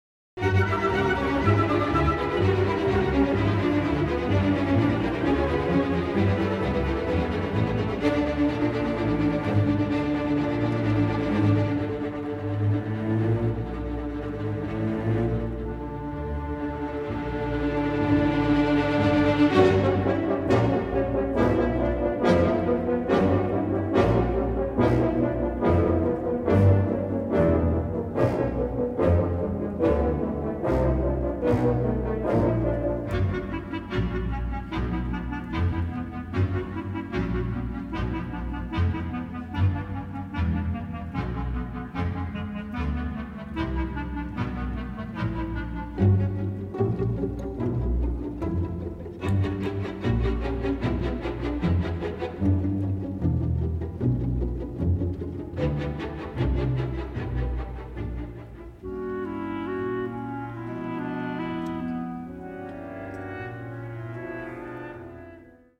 Original Calrec Soundfield - Stereo Microphone Mix